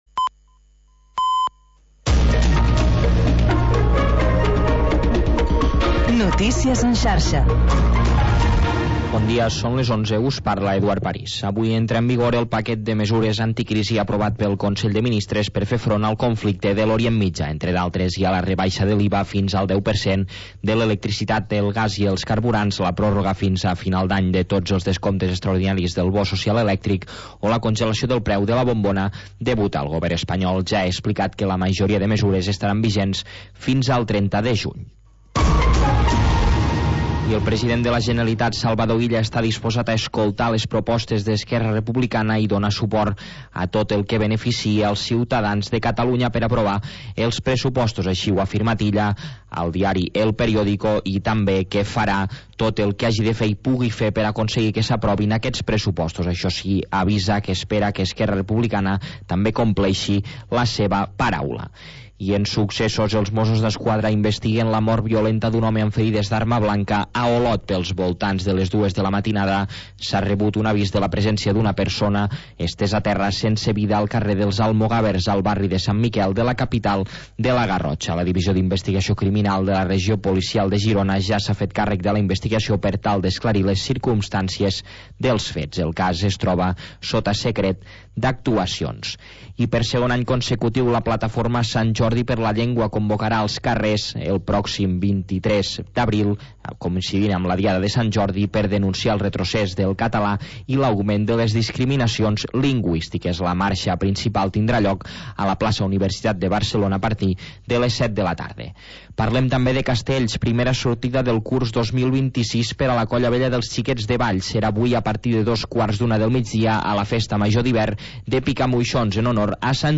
Havanera, cant de taverna i cançó marinera. obrint una finestra al mar per deixar entrar els sons més mariners